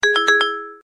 Звук SMS Сообщения Blackberry